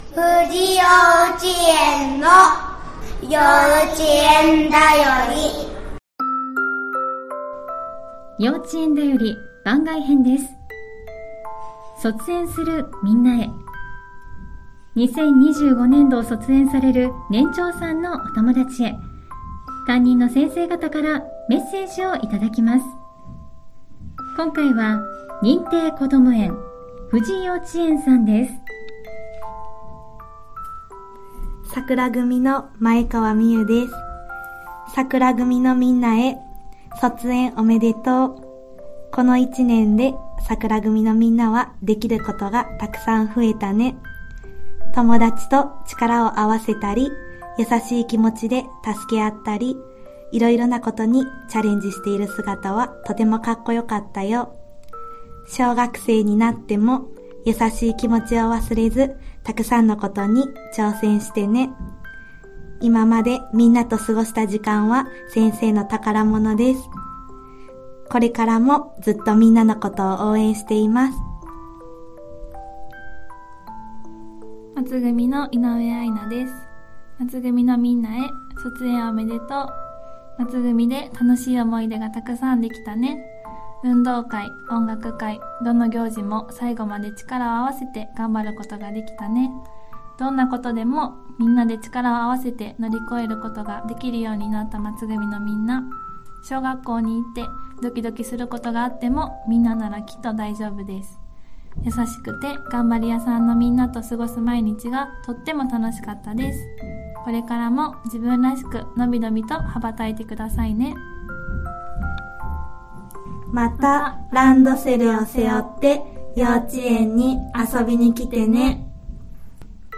2025年度卒園する年長さんへ、先生方からのメッセージをお届けします！